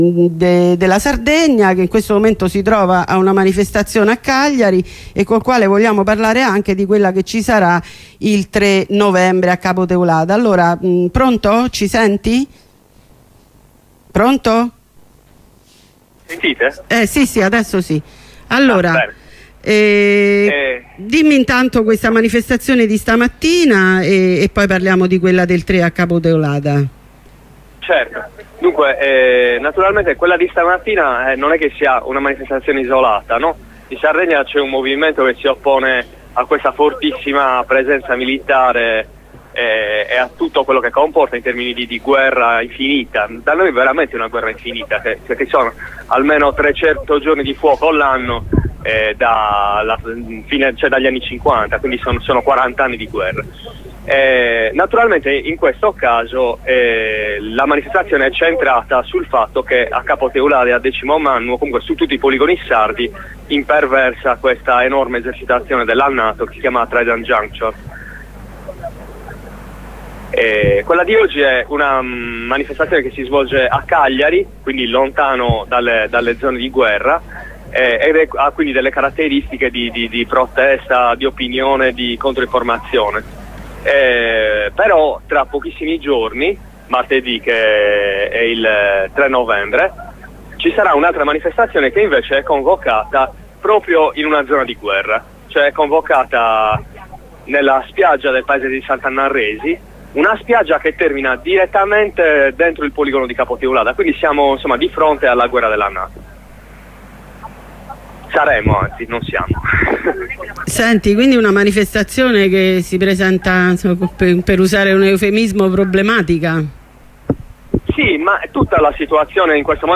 Un compagno dalla Sardegna ci parla della manifestazione del 3 novembre a Capo Teulada contro la Trident Juncture, la più imponente esercitazione Nato degli ultimi 15 anni: scenari di guerra e repressione nei confronti degli e delle attivisti/e.